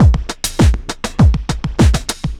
pulse tombeat 100bpm 01.wav